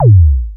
Bassdrum-09.wav